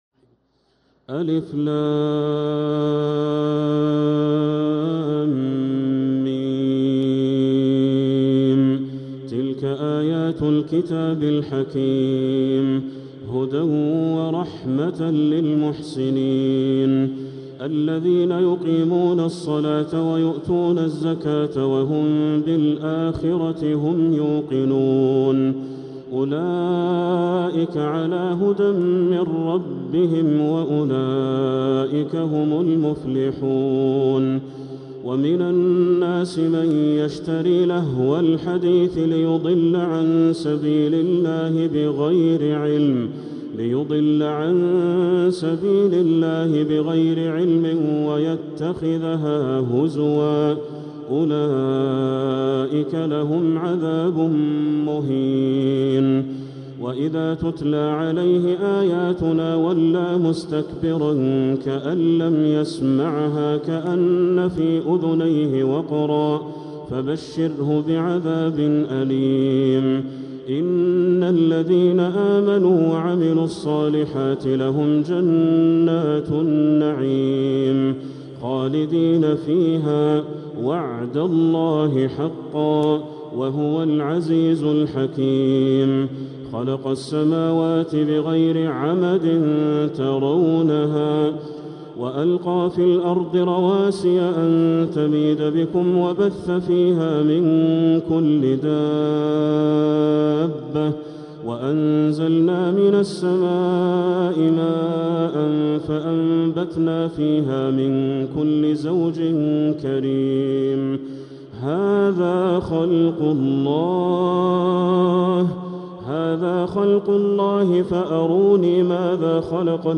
سورة لقمان | مصحف تراويح الحرم المكي عام 1446هـ > مصحف تراويح الحرم المكي عام 1446هـ > المصحف - تلاوات الحرمين